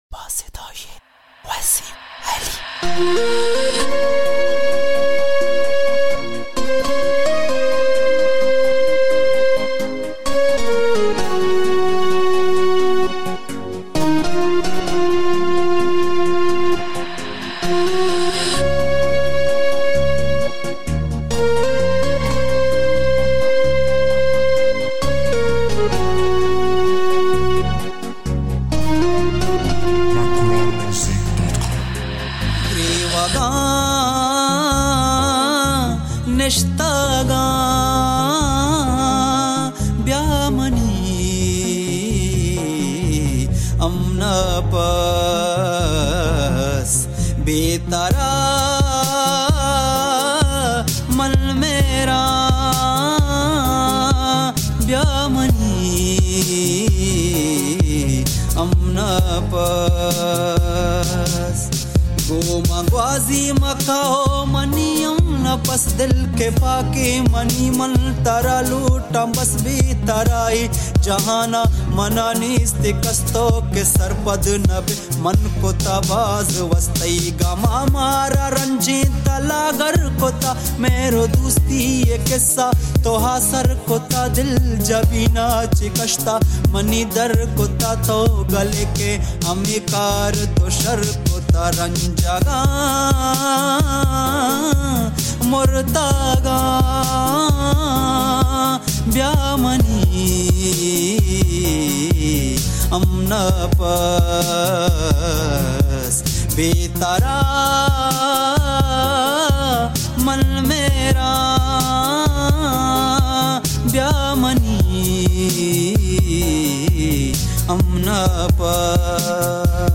اهنگ بلوچی